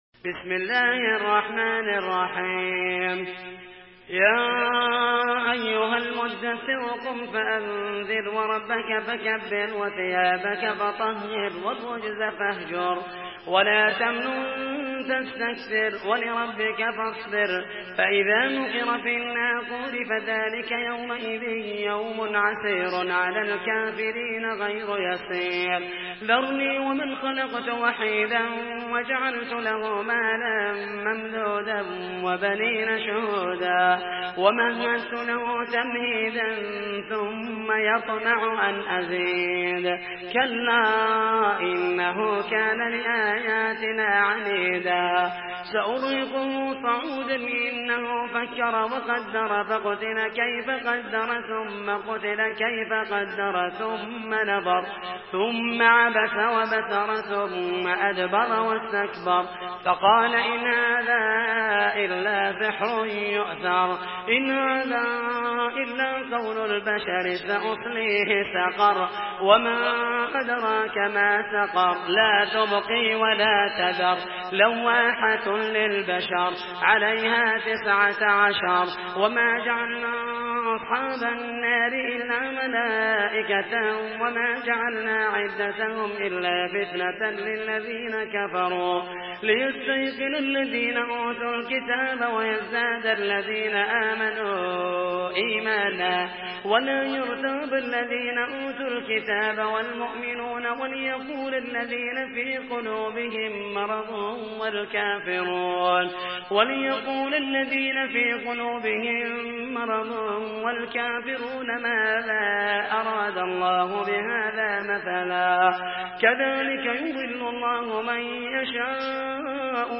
Surah আল-মুদ্দাস্‌সির MP3 by Muhammed al Mohaisany in Hafs An Asim narration.
Murattal Hafs An Asim